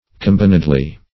combinedly - definition of combinedly - synonyms, pronunciation, spelling from Free Dictionary Search Result for " combinedly" : The Collaborative International Dictionary of English v.0.48: Combinedly \Com*bin"ed*ly\, adv. In combination or cooperation; jointly.